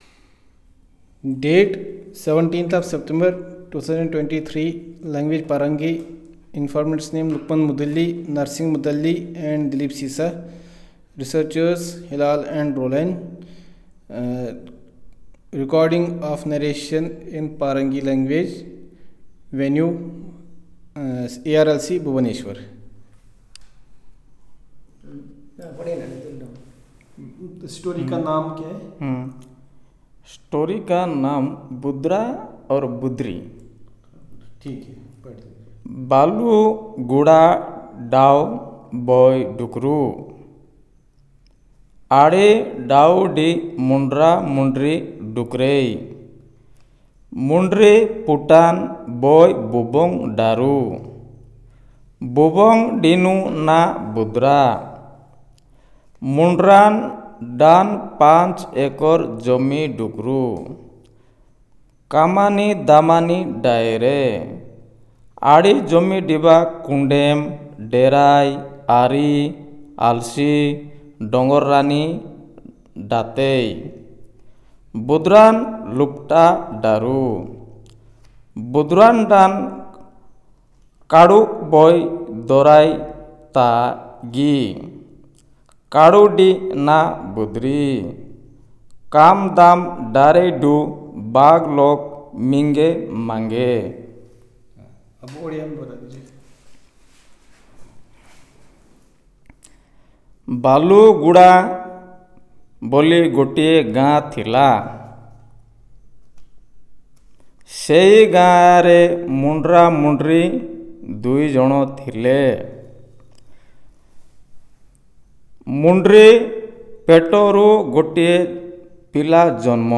Elicitation of Story